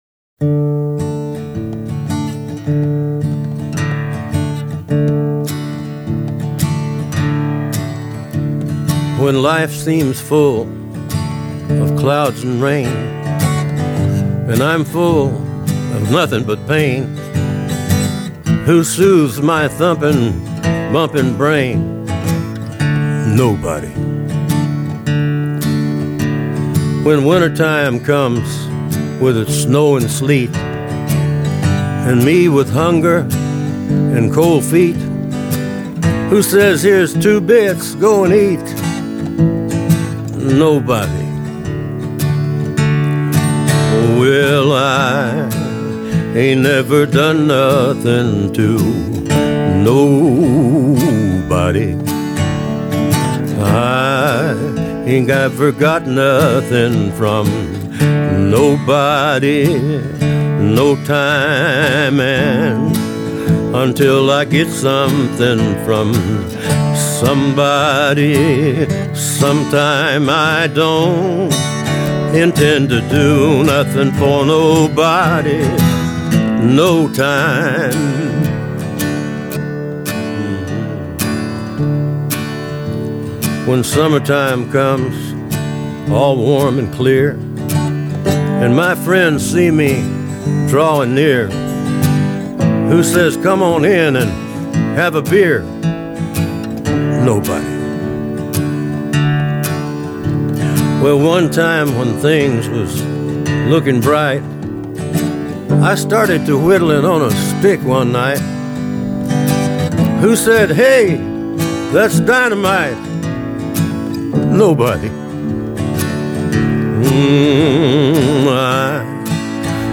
Sad and poignant but funny.